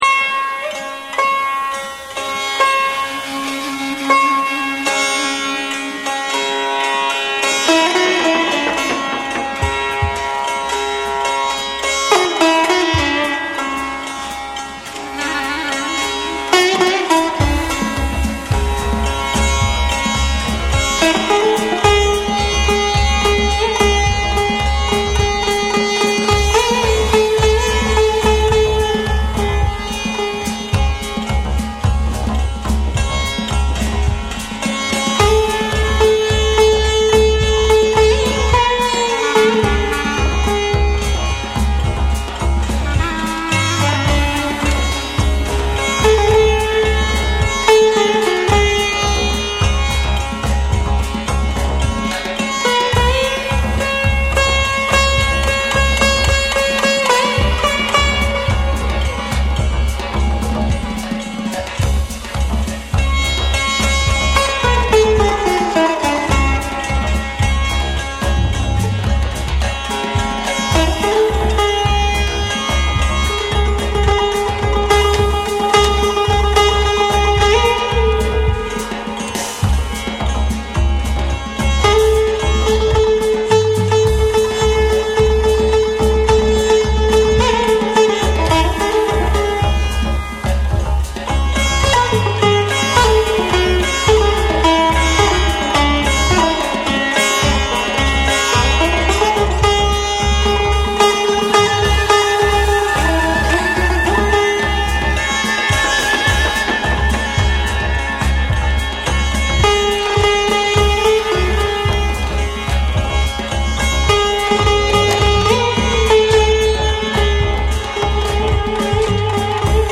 インド古典音楽の神秘性と、ジャズ、サイケ、ダブどが交錯する至高のクロスカルチャー・グルーヴ！
SOUL & FUNK & JAZZ & etc / WORLD